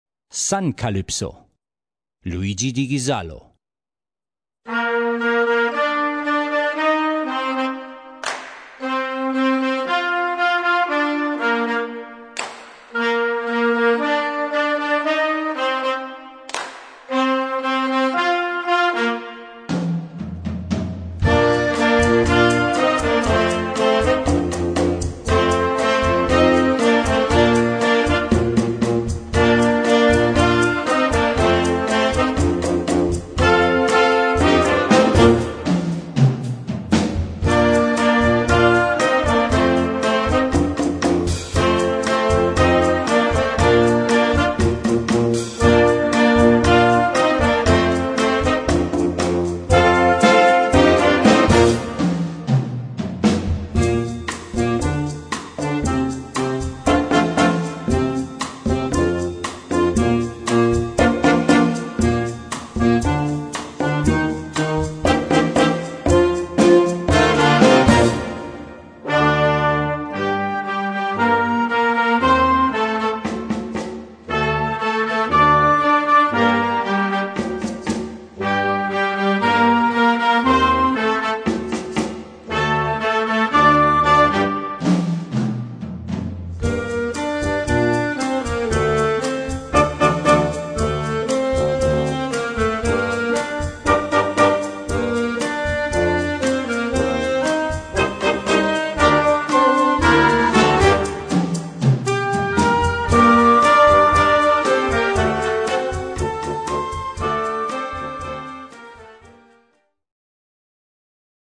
Gattung: Moderner Einzeltitel
Besetzung: Blasorchester
Im Rhythmus sind gefühlsbetonte Akzente versteckt.